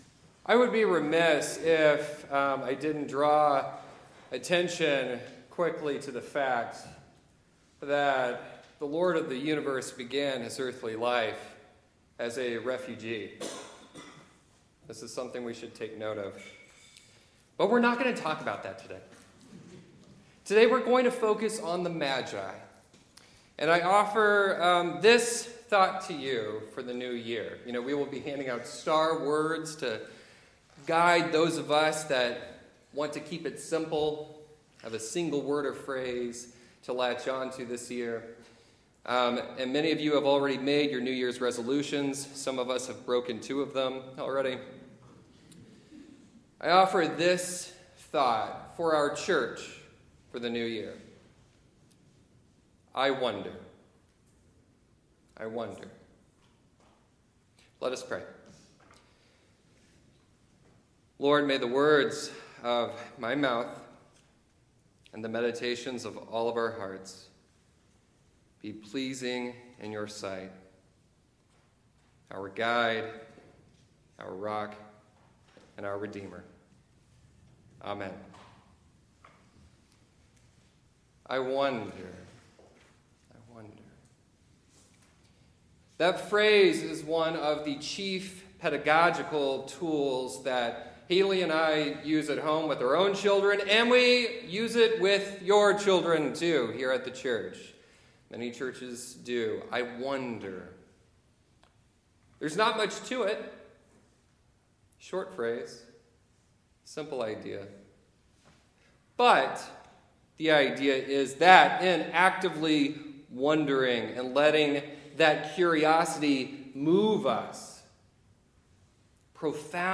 Gospel Lesson Matthew 2:1-23; 28:16-20